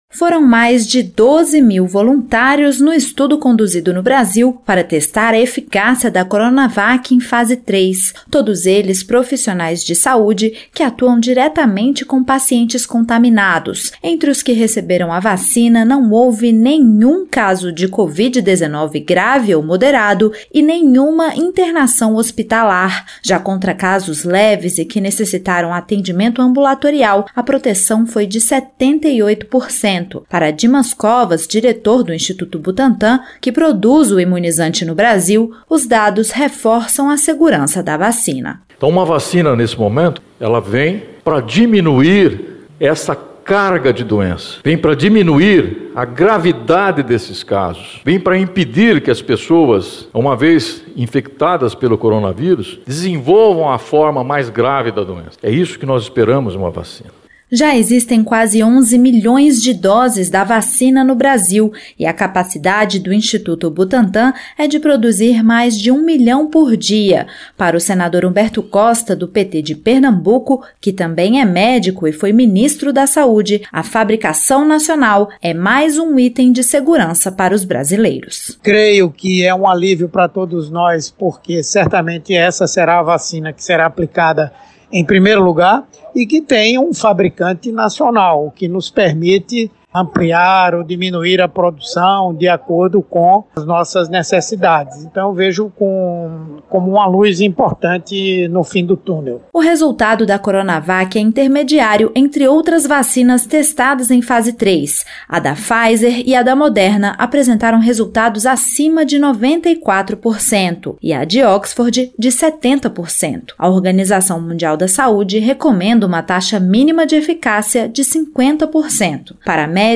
Para os senadores Humberto Costa (PT-PE) e Zenaide Maia (Pros-RN), os resultados dão esperança aos brasileiros.